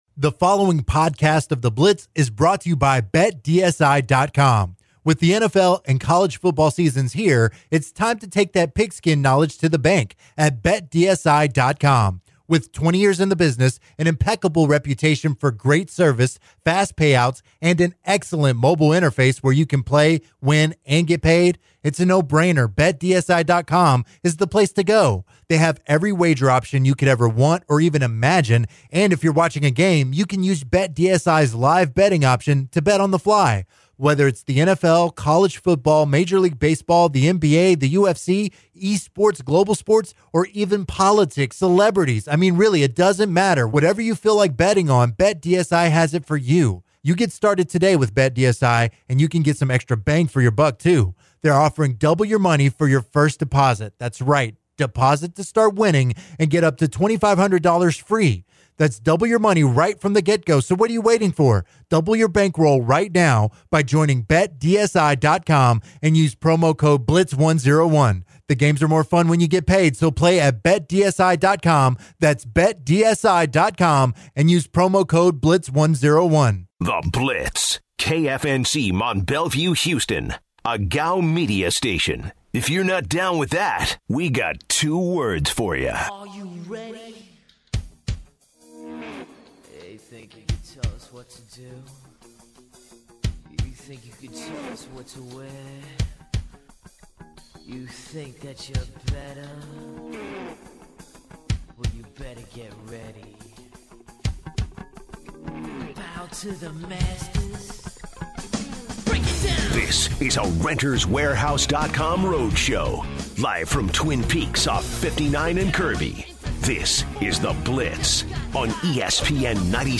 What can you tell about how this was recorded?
Live from Twin Peaks